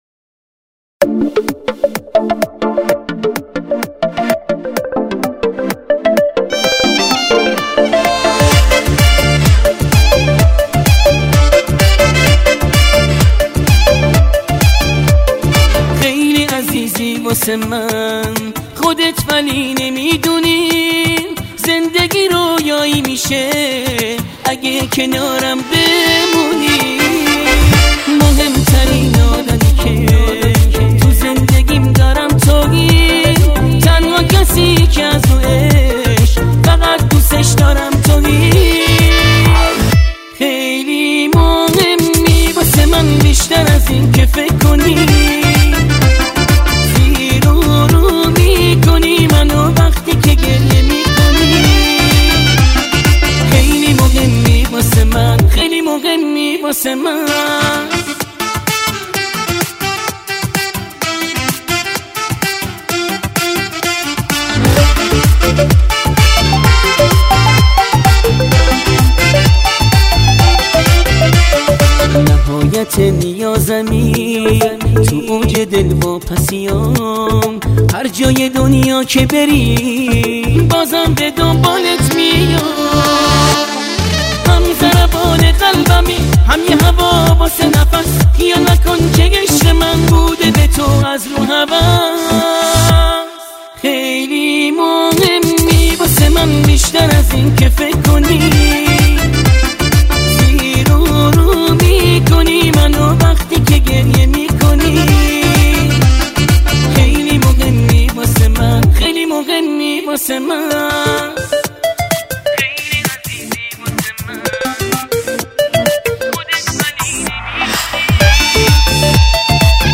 • آهنگ شاد